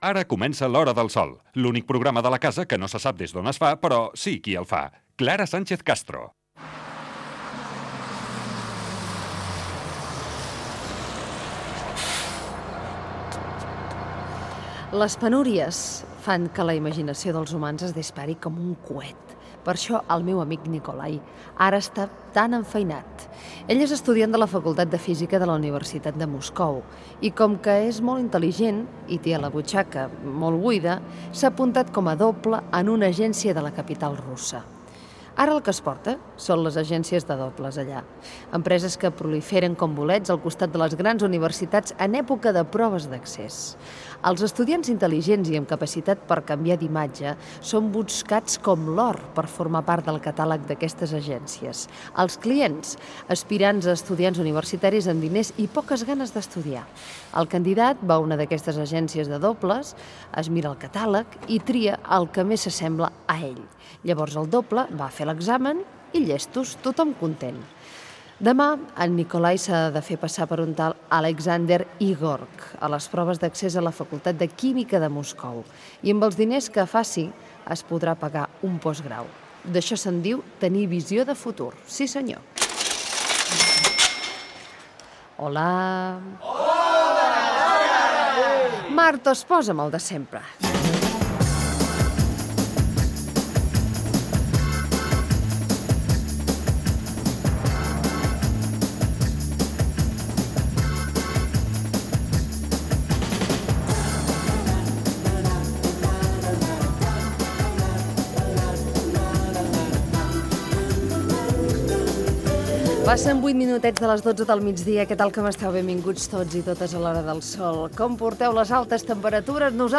Careta del programa, comentari sobre els dobles d'estudiants que cobren per fer exàmens, hora, presentació i conversa amb els redactors del programa
Entreteniment
Fragment extret de l'arxiu sonor de COM Ràdio